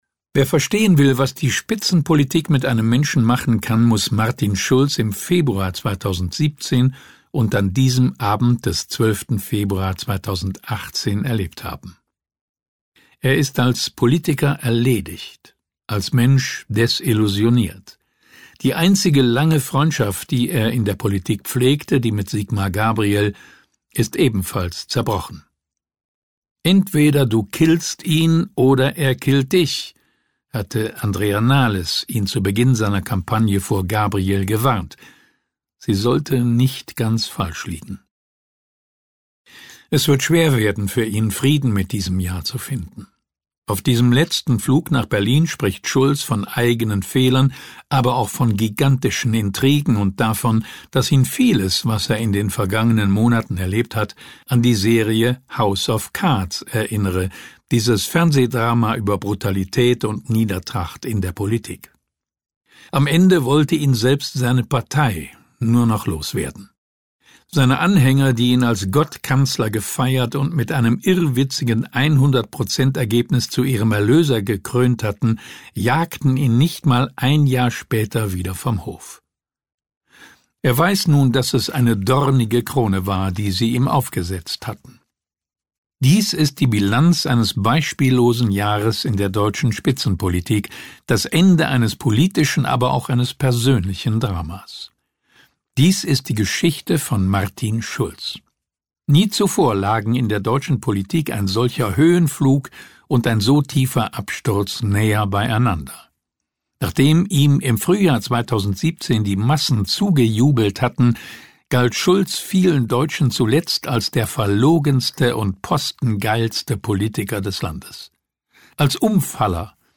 Das Hörbuch zur »Reportage des Jahres«